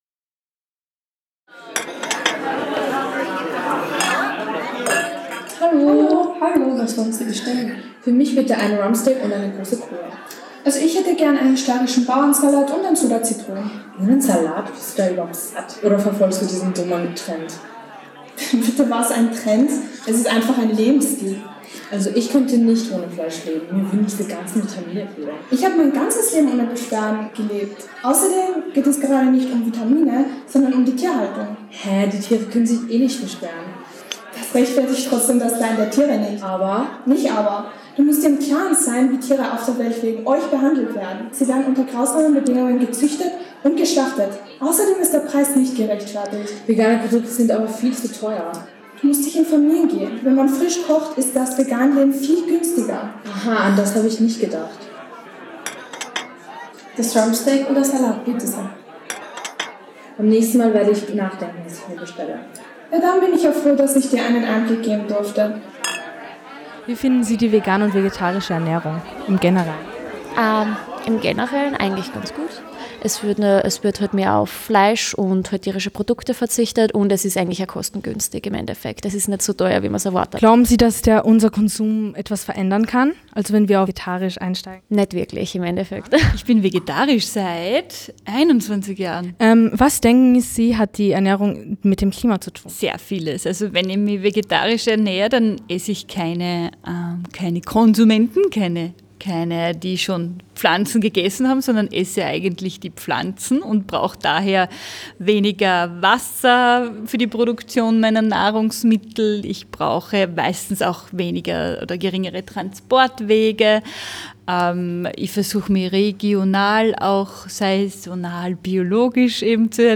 Mini-Feature aus dem Workshop